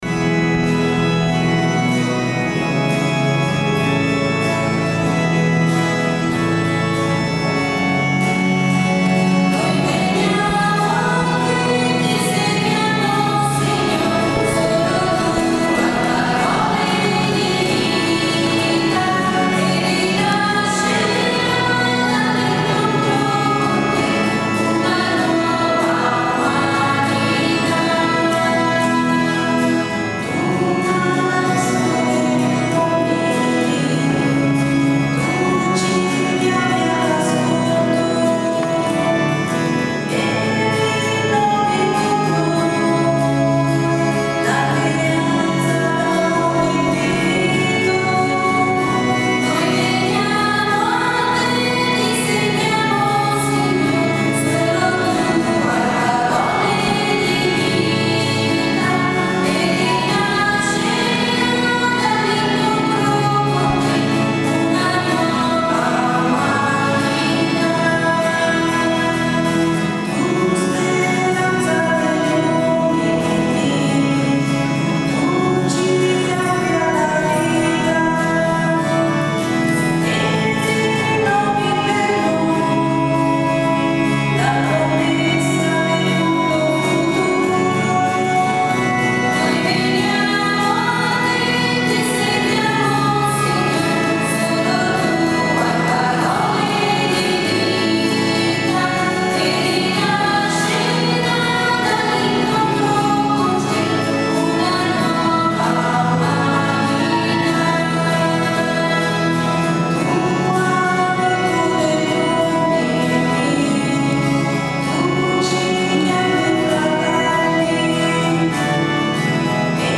Poi la Messa,
Canto Iniziale